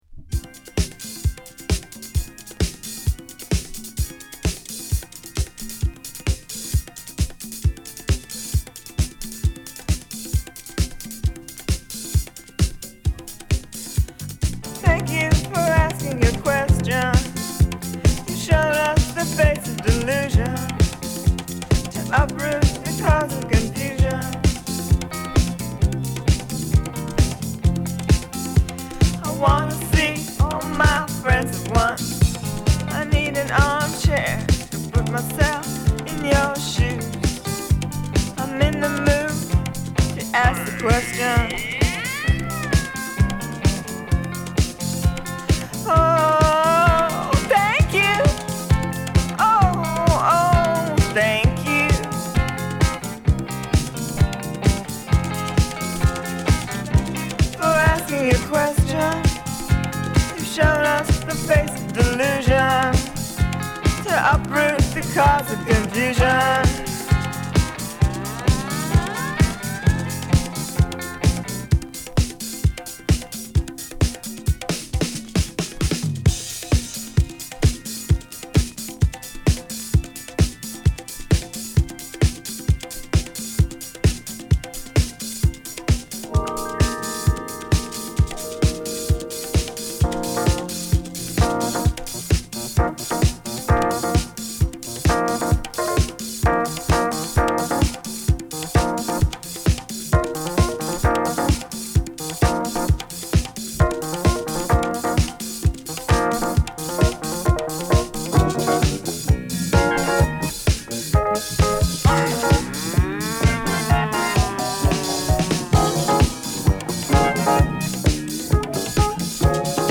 」等、ダンスミュージックに新しい概念をもたらした変態ディスコファンクの金字塔アルバム！！